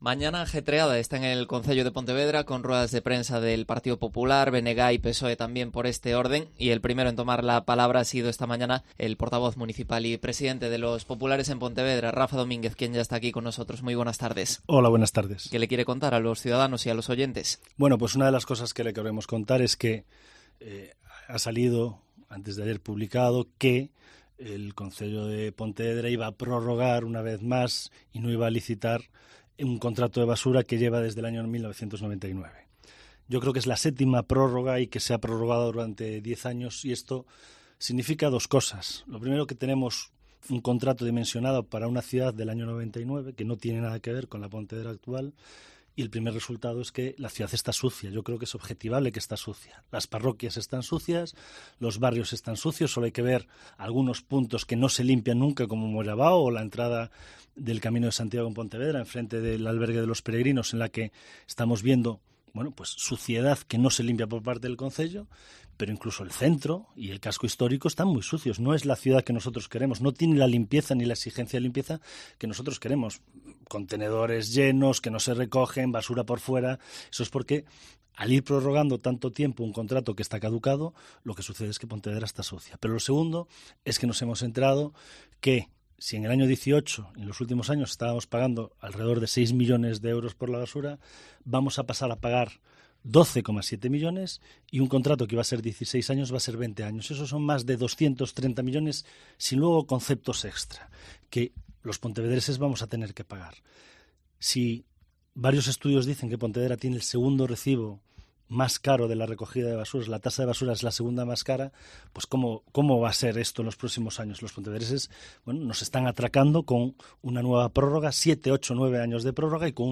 Entrevista a Rafa Domínguez, presidente del PP de Pontevedra